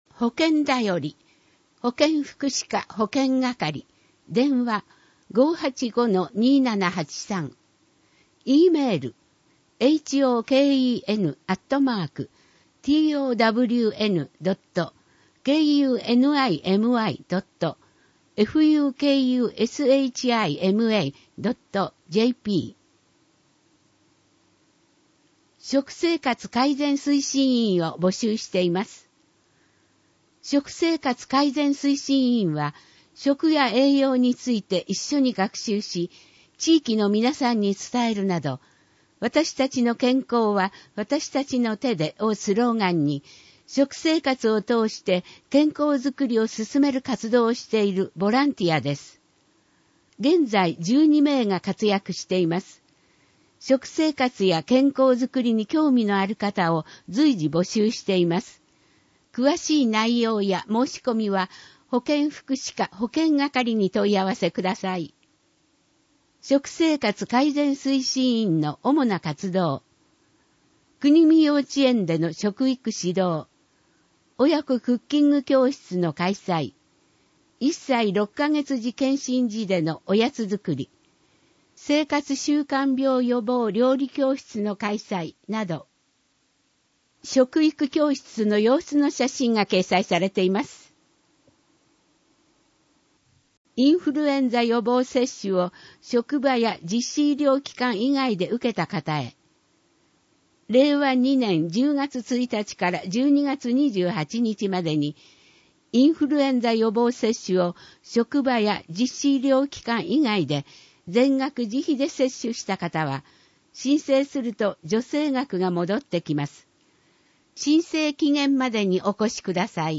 ＜外部リンク＞ 声の広報 広報紙の内容を音声で提供しています。